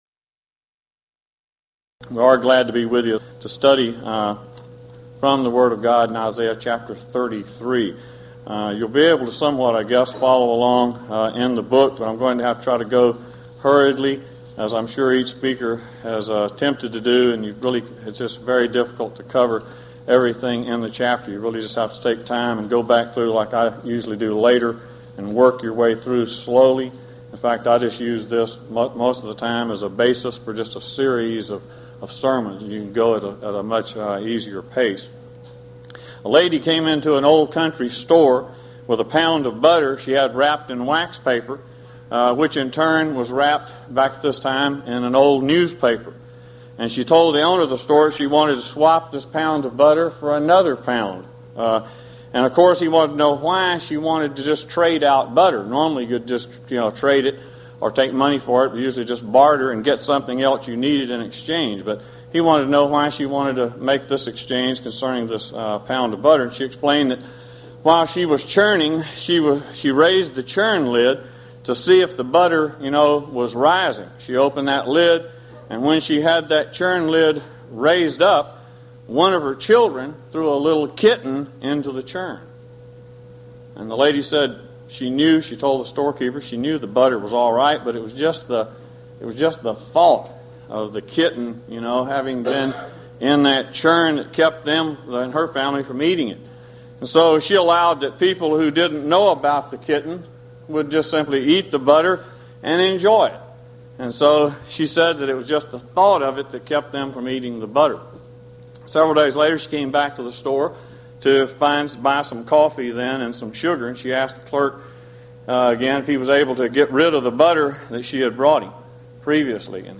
Series: Houston College of the Bible Lectures Event: 1995 HCB Lectures Theme/Title: The Book Of Isaiah - Part I